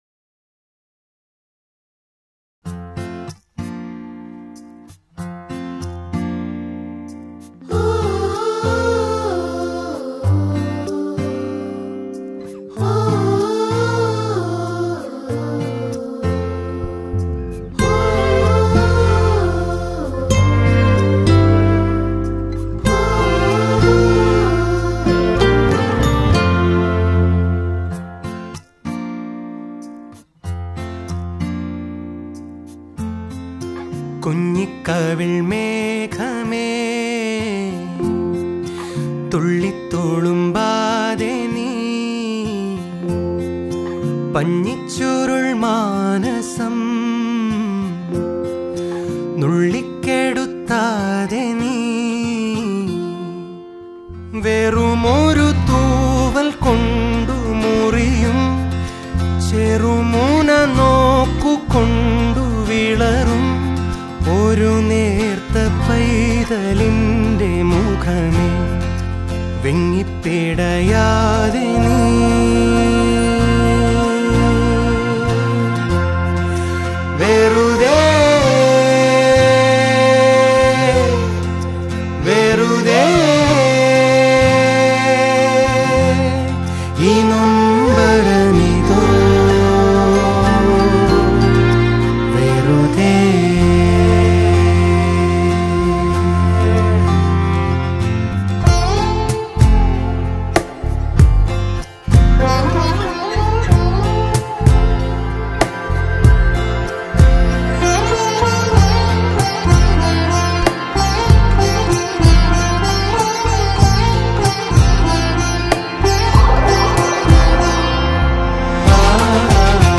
Kids Chorus
Guitars
Flute
Sitar
Sarangi
String Quartet
Drums